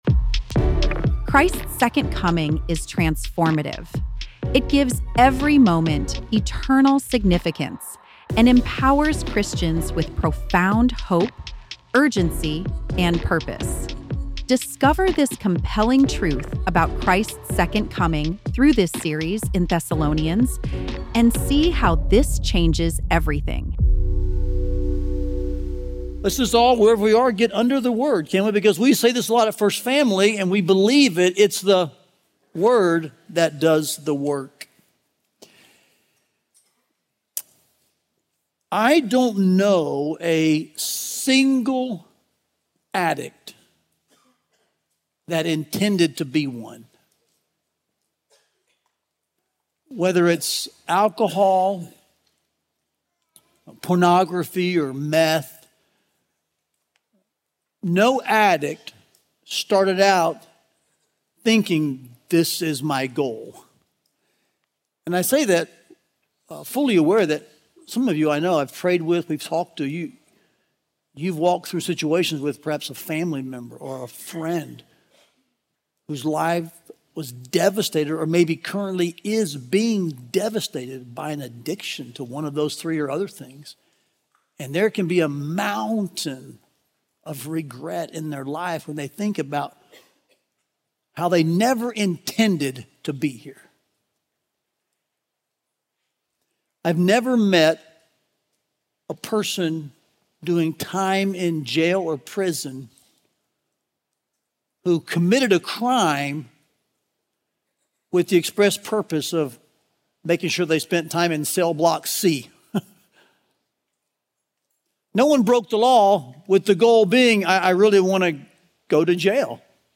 Listen to the latest sermon and learn more about this preaching series here.